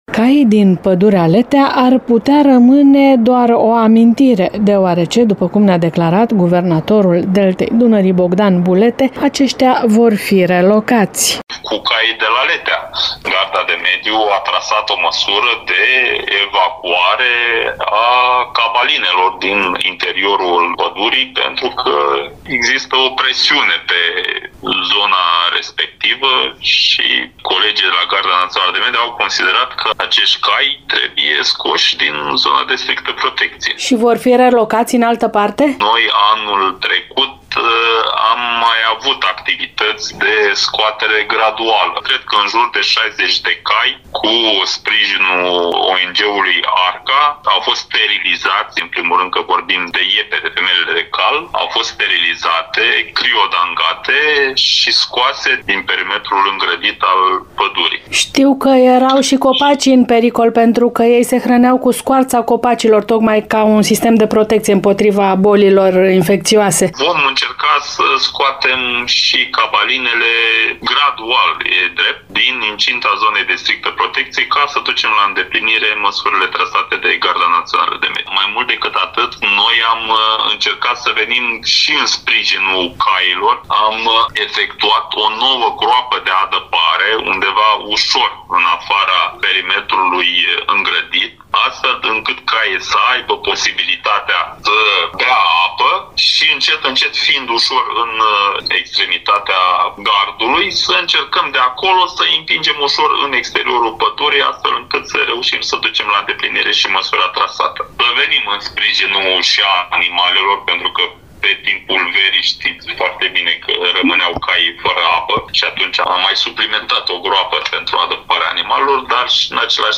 Guvernatorul ARBDD, Bogdan Bulete.